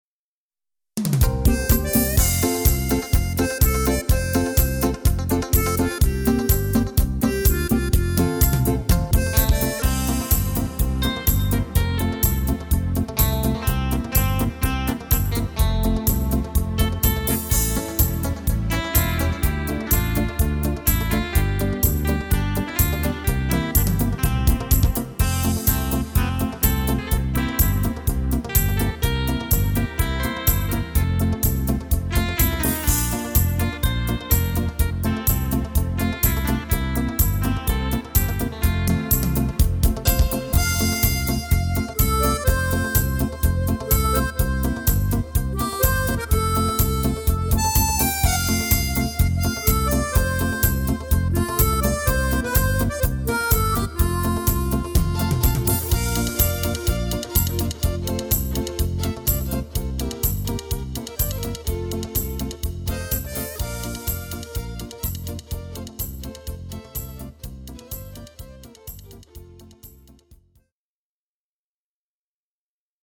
HD = Hoedown/Patter